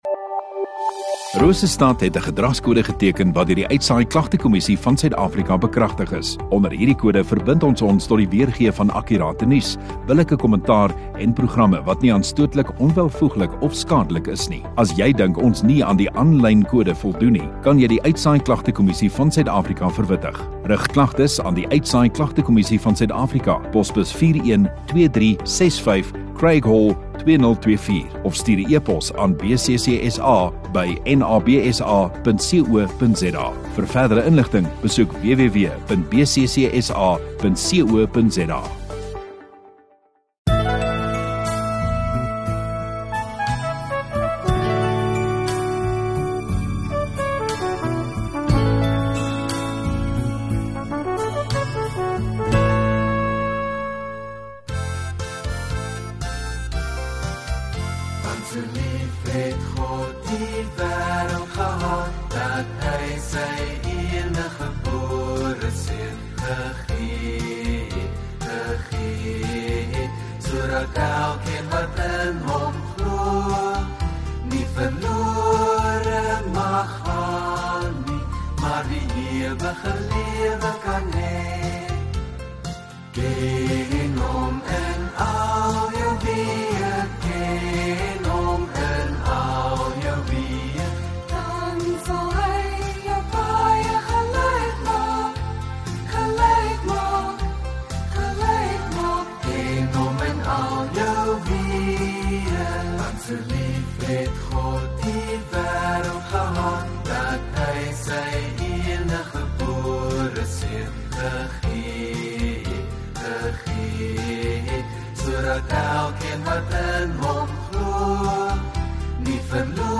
3 May Saterdag Oggenddiens